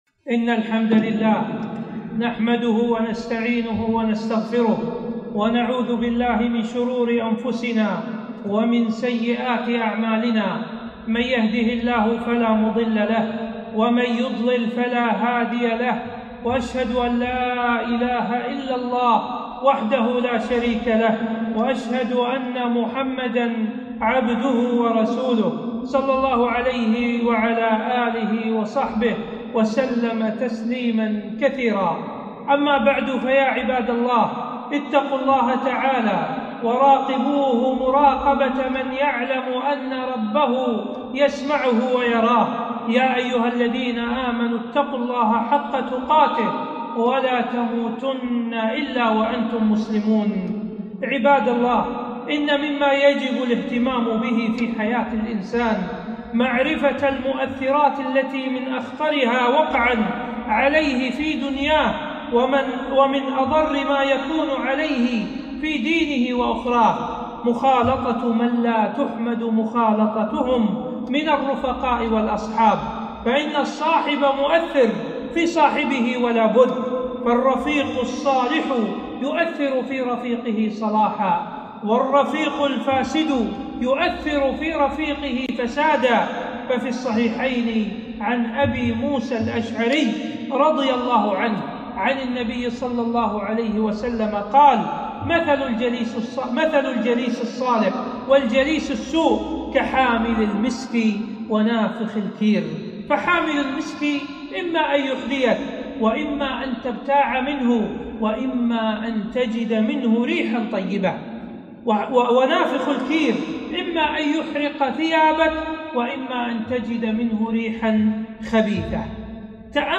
خطبة - التحذير من رفقاء السوء